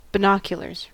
Ääntäminen
IPA : /ˈbɪnˌɒk.jʊ.lə(ɹ)z/